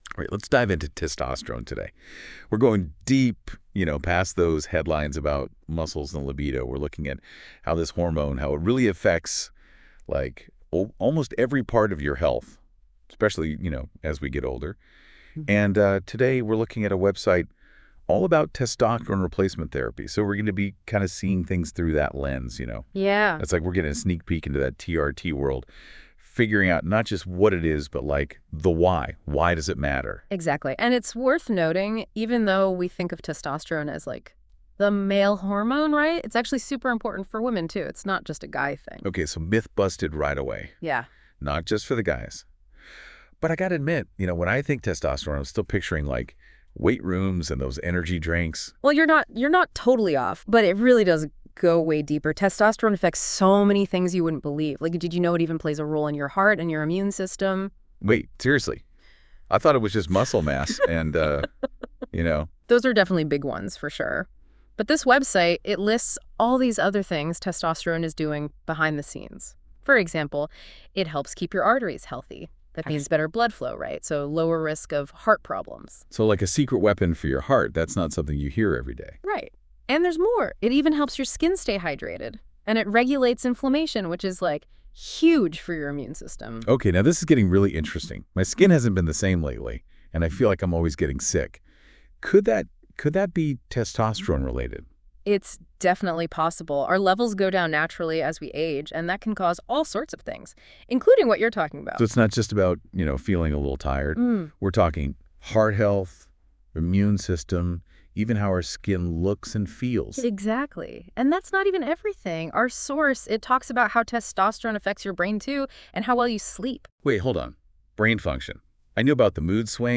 Discover the science behind Testosterone Replacement Therapy (TRT) and how it can help optimize your energy, strength, and overall well-being. Tune in to our expert-led podcast interview for insights on TRT solutions, benefits, and what to expect on your journey to better health.